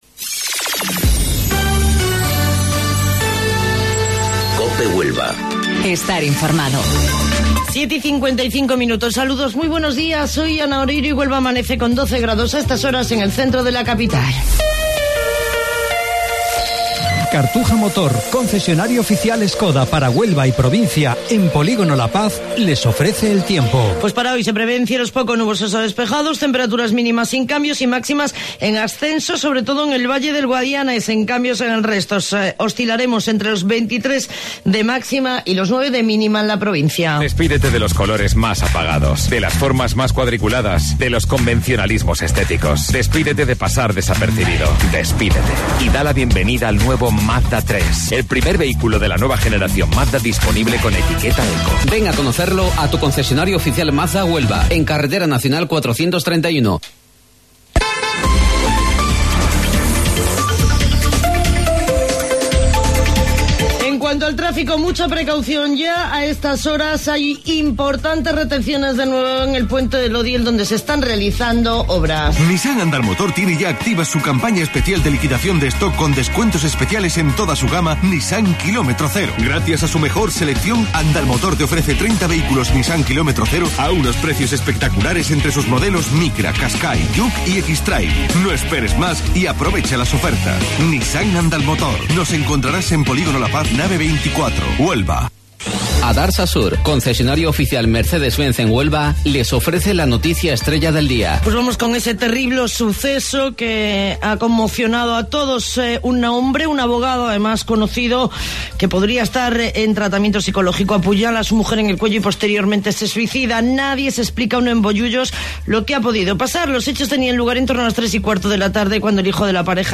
AUDIO: Informativo Local 07:55 del 14 de Marzo